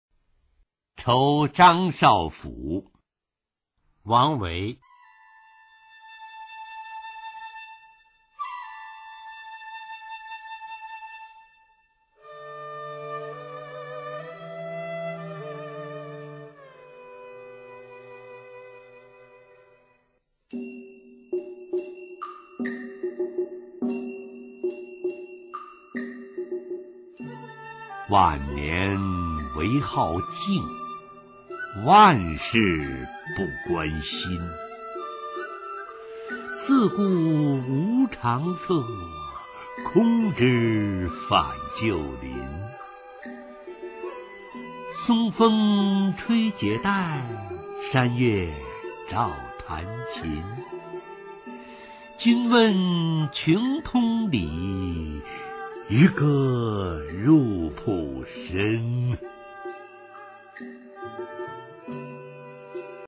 [隋唐诗词诵读]王维-酬张少府 配乐诗朗诵